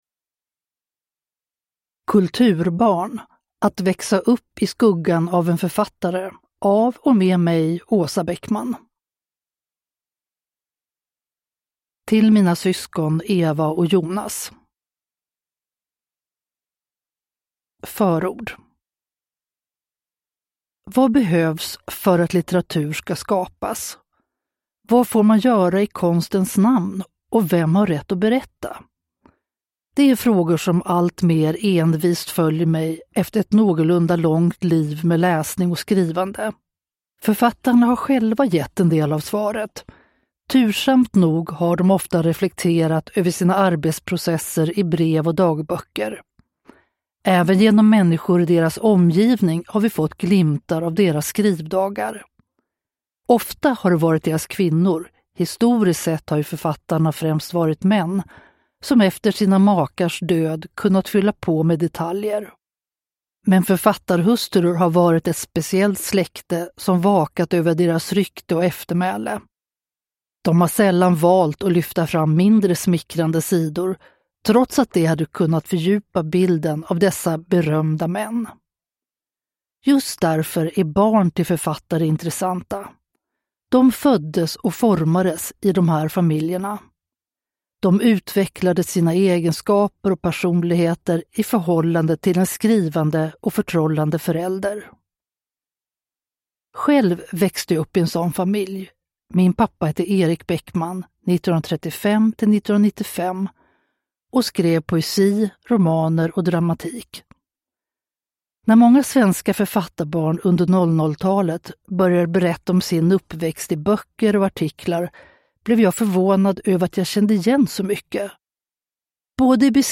Kulturbarn : att växa upp i skuggan av en författare – Ljudbok